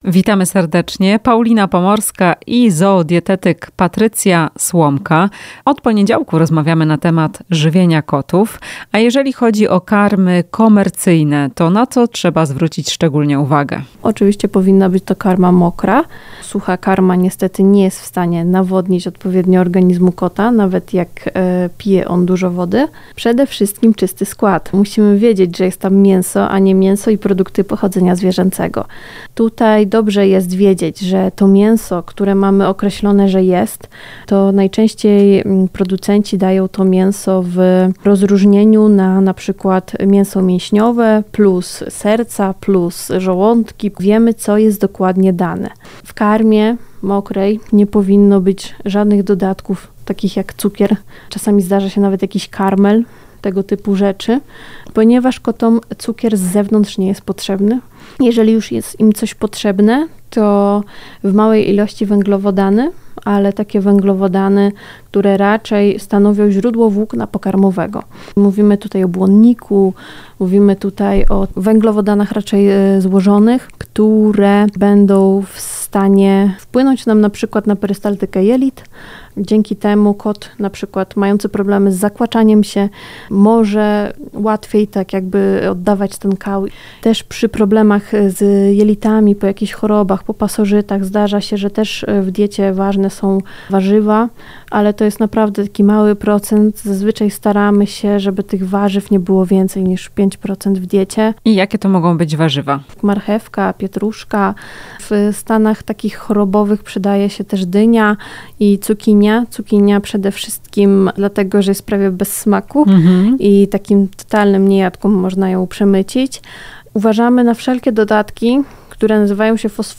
Na co zwrócić uwagę wybierając karmy komercyjne dla kota - w rozmowie